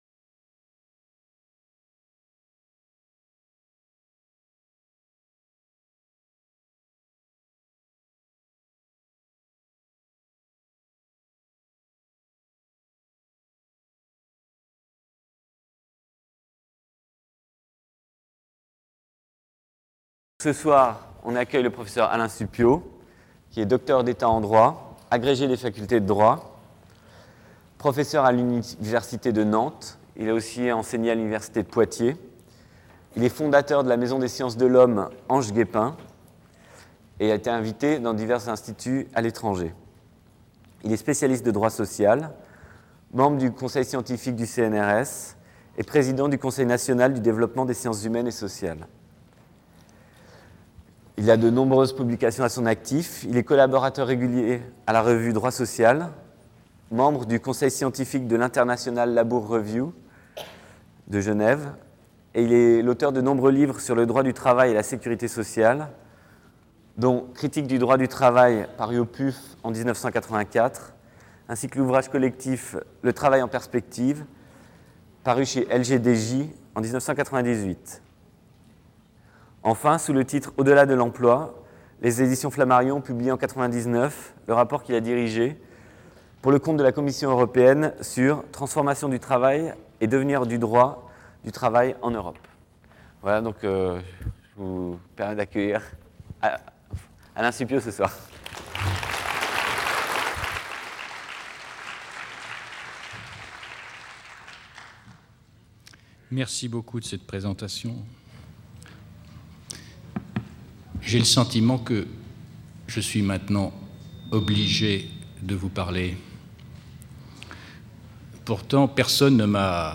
Conférence du 22 février 2000 par Alain Supiot. L'idée s'est affirmée en Occident depuis deux siècles que le contrat serait l'aboutissement indépassable d'un progrès historique arrachant l'Homme aux sujétions des statuts pour le faire accéder à la liberté.